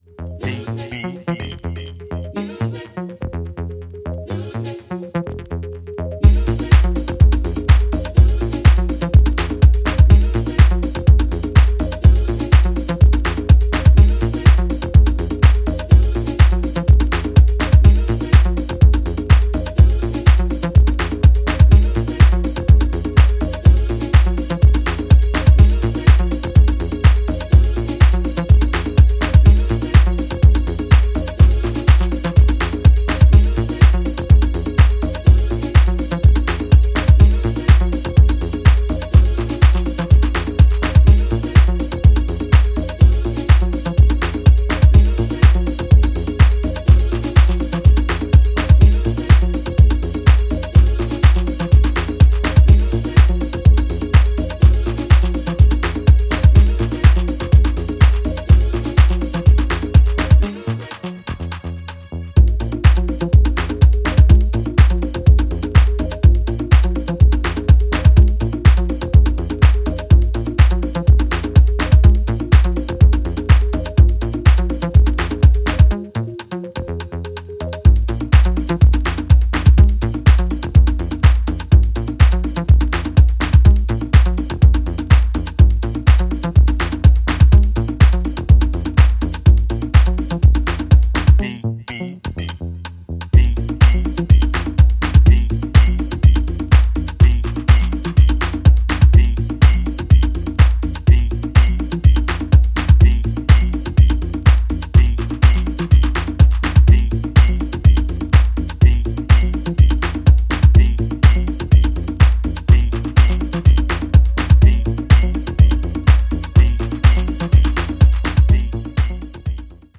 ラフな質感とメランコリーがフロアに浸透していく、非常にエッセンシャルな内容です！